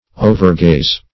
Search Result for " overgaze" : The Collaborative International Dictionary of English v.0.48: Overgaze \O`ver*gaze"\, v. t. To gaze; to overlook.